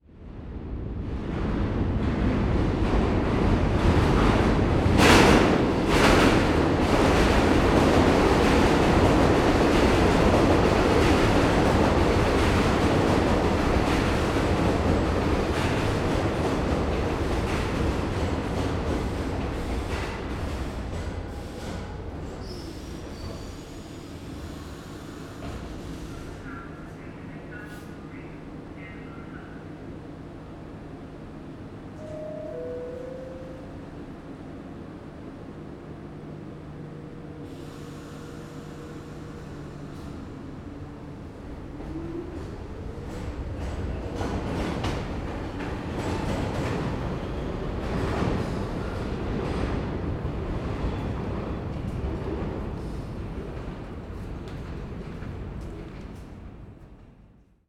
Metro_poezd (открыта)